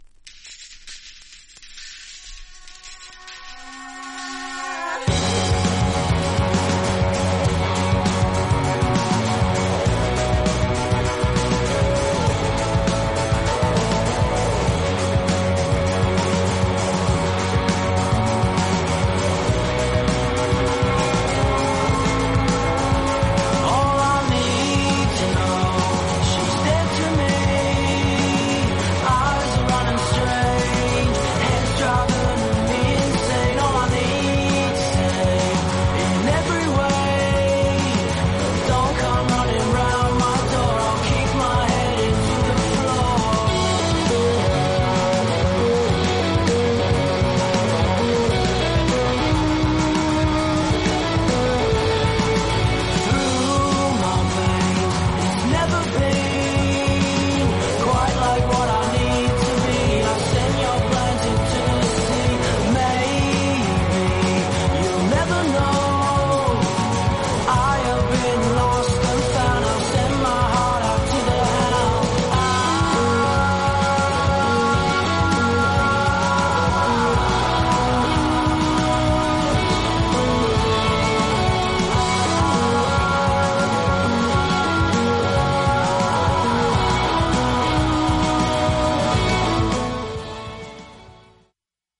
実際のレコードからのサンプル↓ 試聴はこちら： サンプル≪mp3≫